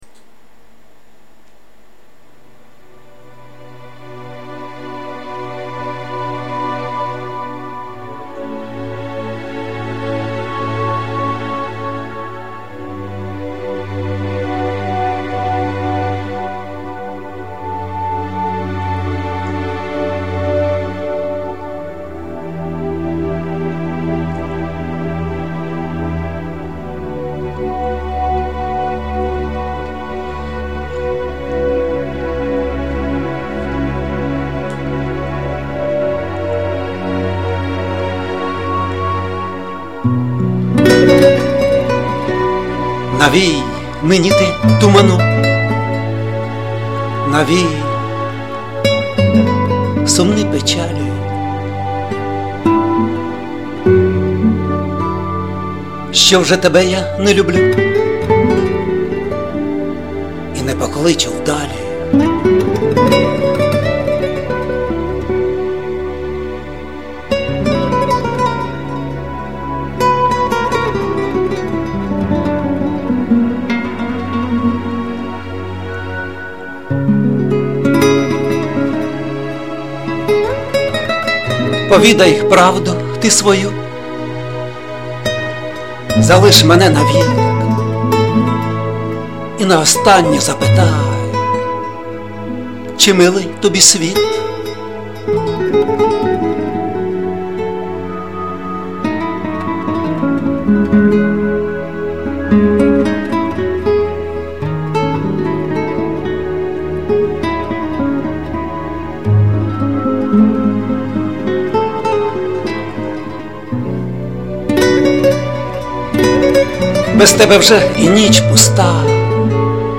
паузи в записі напрягають